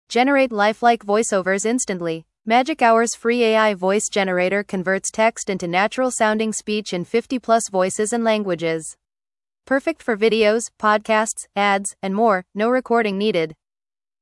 Magic Hour’s free AI Voice Generator converts text into natural-sounding speech in 50+ voices and languages.